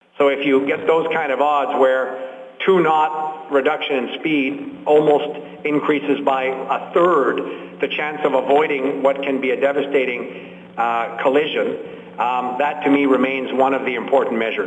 En conférence de presse, le 23 janvier, le ministre des Pêches et des Océans, Dominic LeBLanc, a lui-même indiqué qu’une diminution de vitesse de seulement deux nœuds augmente de 30 pour cent la probabilité que les mammifères évitent une collision avec les navires :